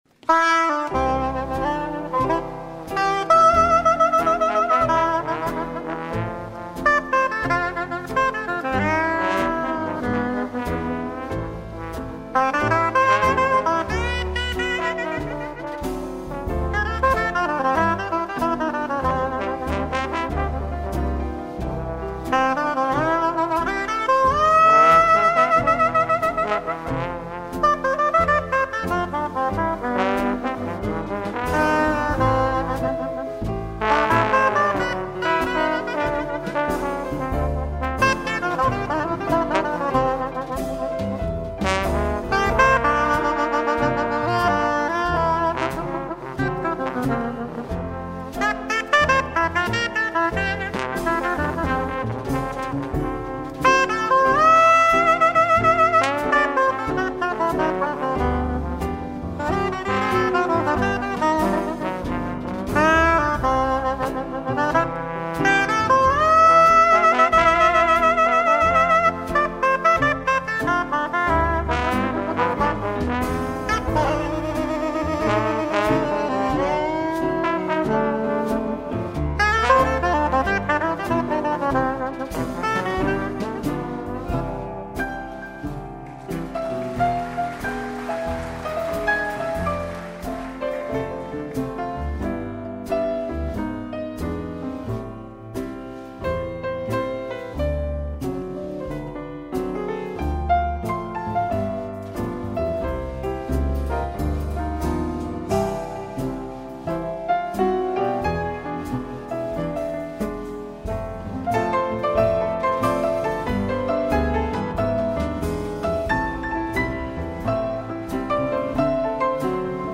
en concert
saxophone soprano
trombone
contrebasse
batterie